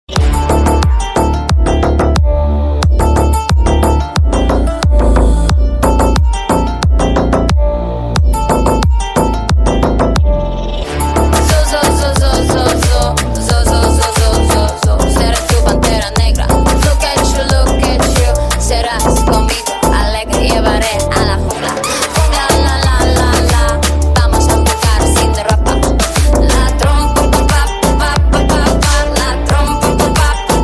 vibrant pop song